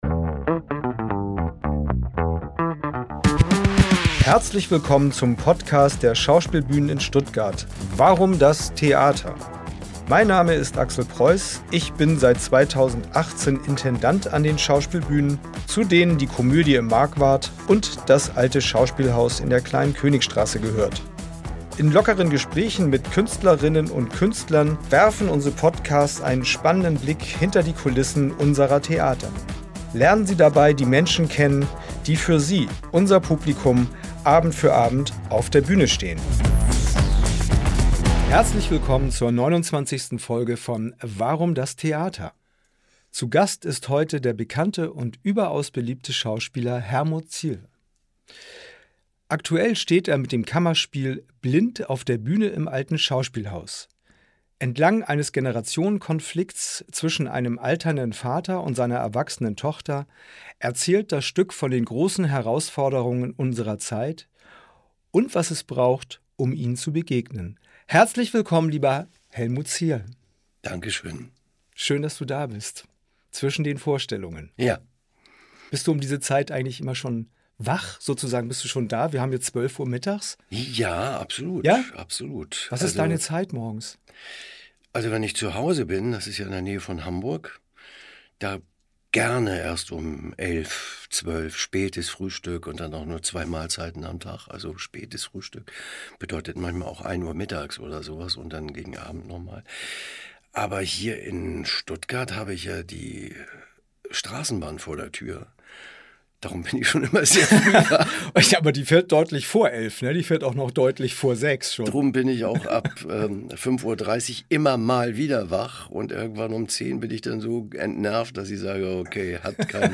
Unser Schauspielbühnen-Talk präsentiert Ihnen regelmäßig Gespräche mit unseren Künstlerinnen und Künstlern. Lernen Sie die Menschen hinter den Bühnencharakteren kennen und erfahren Sie, wie Theater hinter den Kulissen funktioniert.